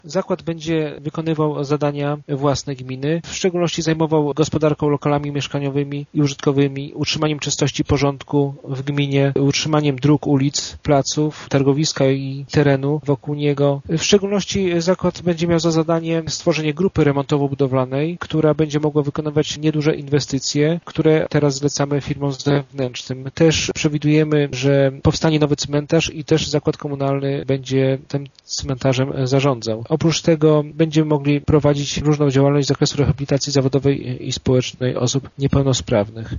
Dzięki temu niektóre zadania samorząd realizowałby szybciej i taniej – tłumaczy zastępca burmistrza Ireneusz Wilczyński: